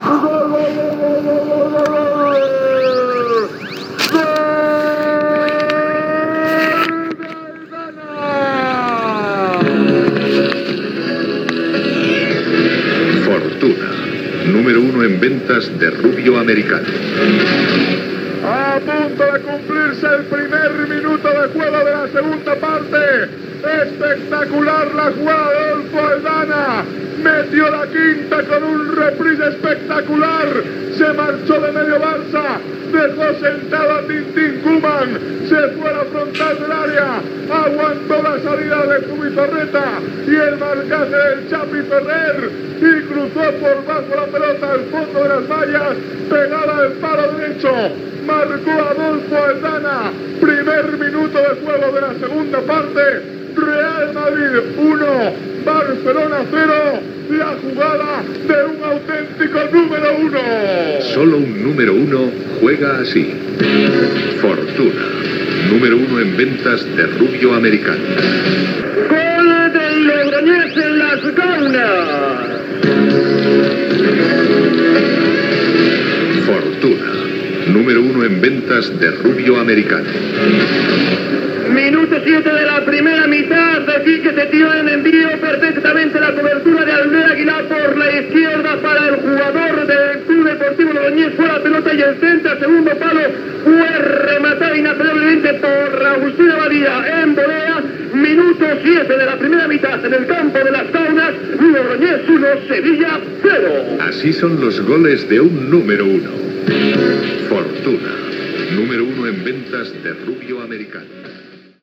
Publicitat, i gols als partits entre el Real Madrid i el Futbol Club Barcelona i el Logroñés amb el Sevilla
Esportiu
FM